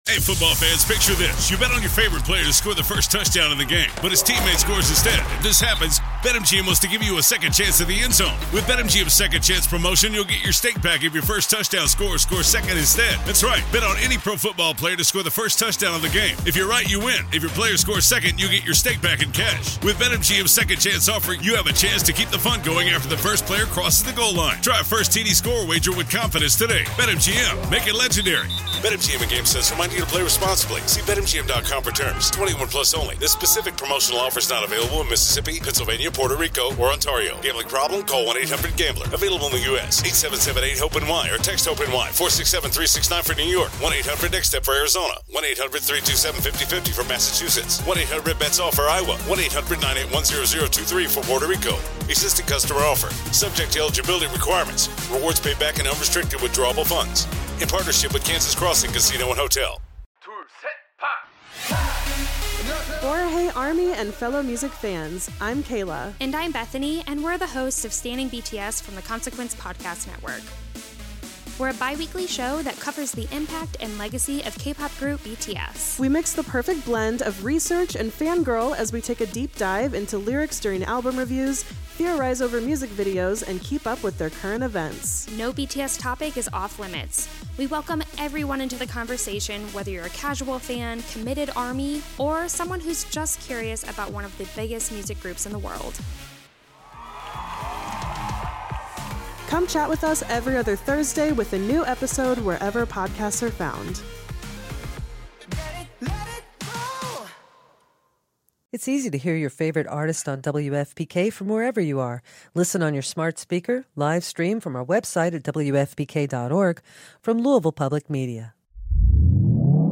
Listen to Catalina Sandino Moreno and Sasha Calle chat about all this and more or watch both interviews on YouTube.